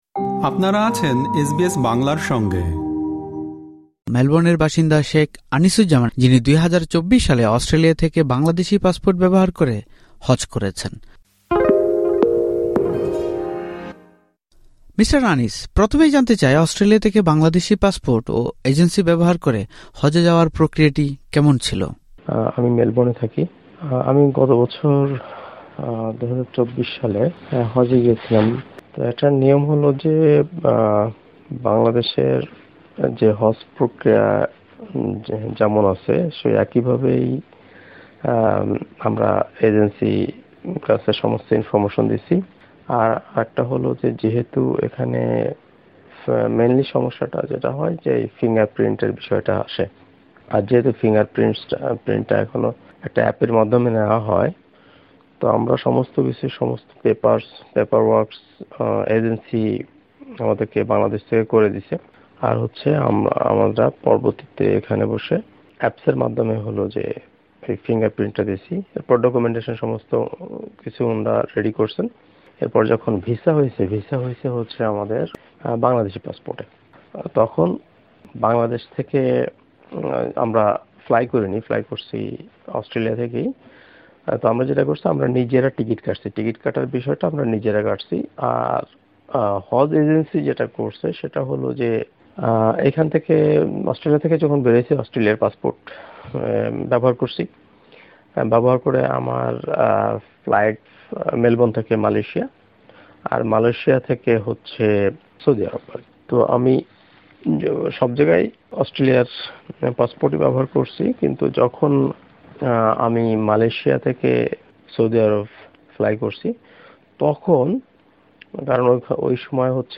এই পডকাস্টে তারা বর্ণনা করছেন তাদের অভিজ্ঞতা।